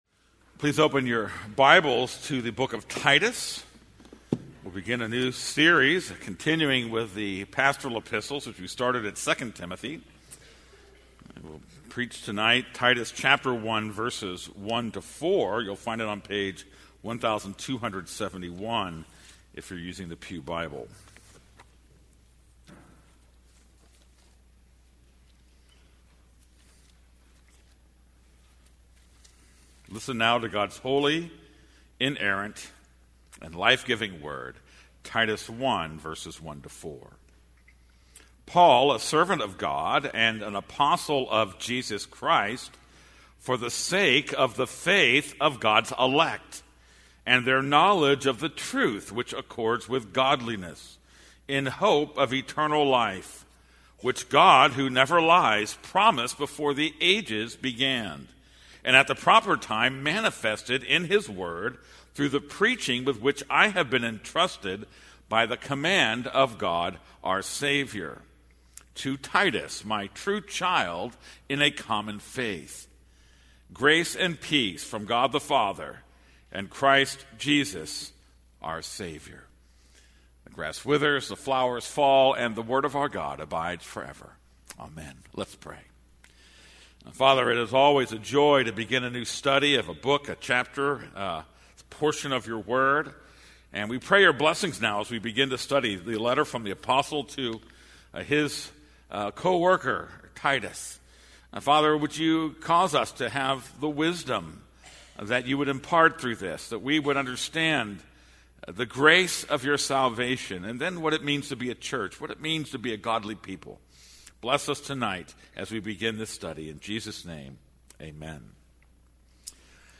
This is a sermon on Titus 1:1-4.